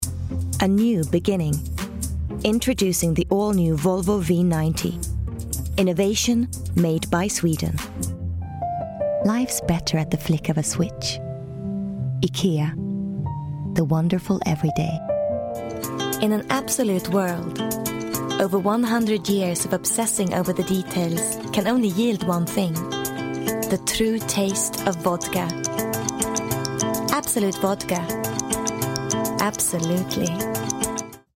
Actress, youthful, experienced, from animation to audiobooks.
Scandi accented English Commercials